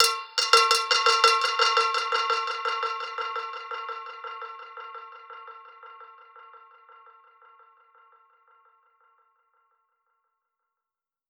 Index of /musicradar/dub-percussion-samples/85bpm
DPFX_PercHit_B_85-12.wav